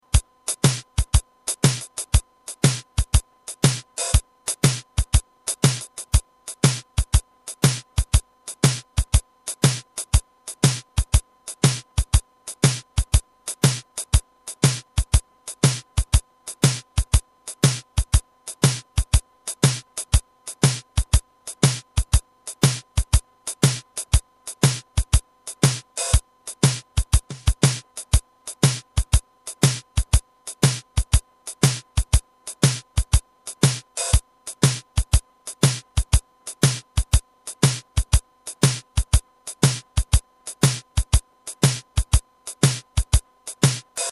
There is a drum beat keeping the rhythm.
Try using the karaoke version of the audio and modelling it in your own voice.